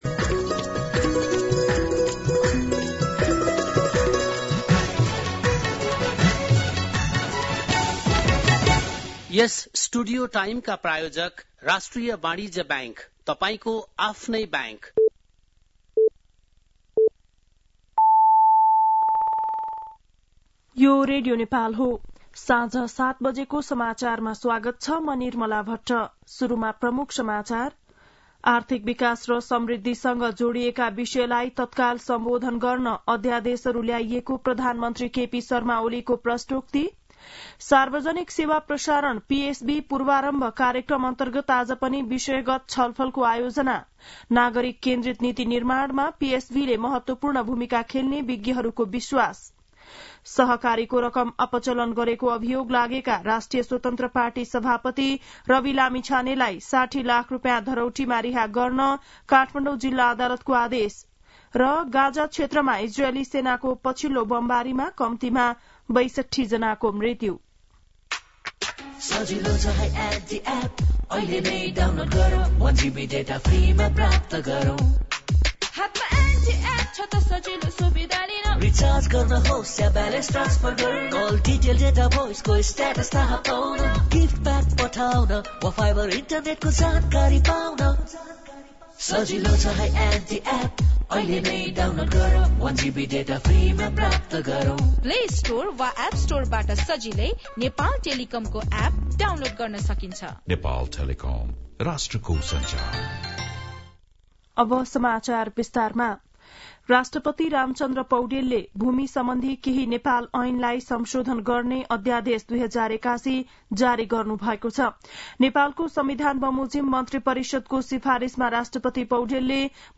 बेलुकी ७ बजेको नेपाली समाचार : ३ माघ , २०८१
7-pm-news-1.mp3